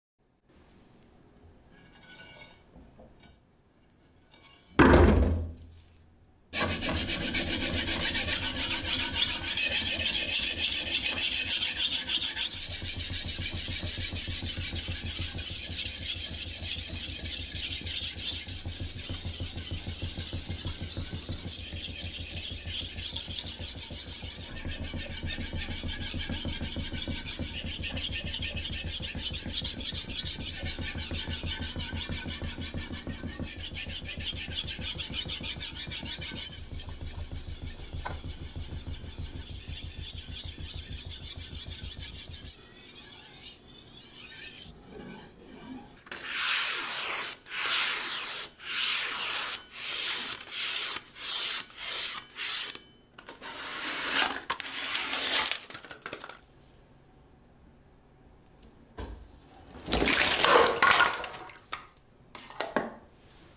Unintentional Asmr Tingles